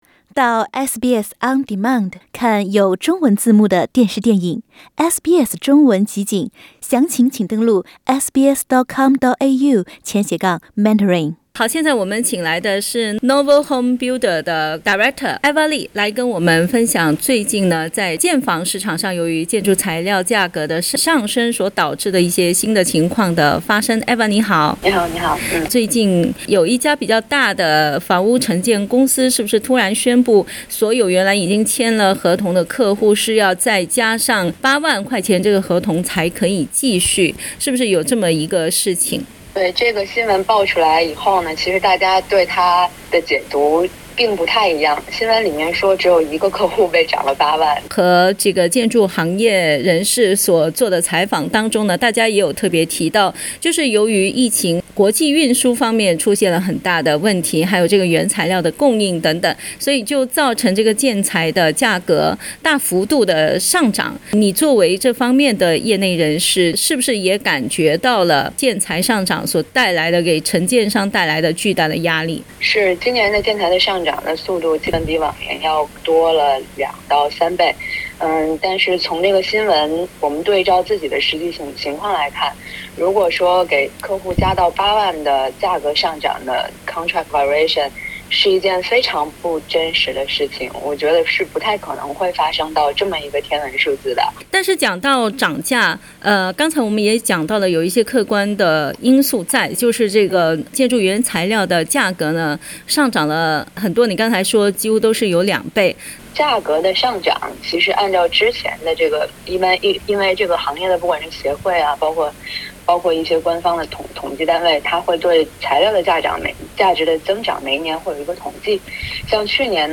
最近據報道，澳洲一家名氣不小的承建商要求客戶補交8萬澳元的費用，否則不再繼續執行原來籤好的建房合同。（點擊圖片收聽寀訪）